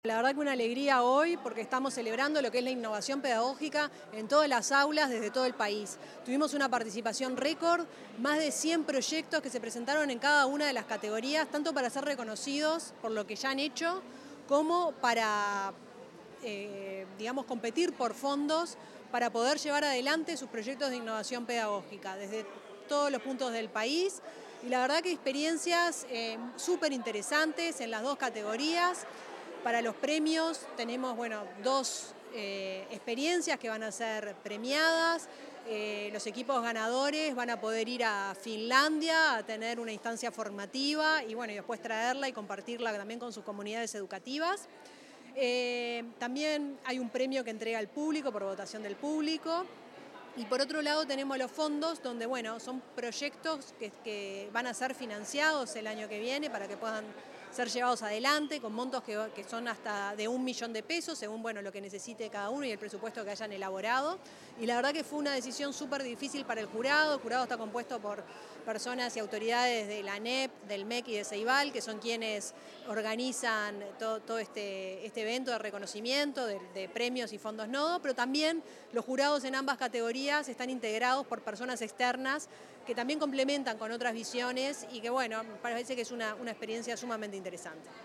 Declaraciones de la presidenta de Ceibal, Fiorella Haim
Antes de participar de la ceremonia de premiación Nodo 2025, la presidenta de Ceibal, Fiorella Haim, dialogó con la prensa.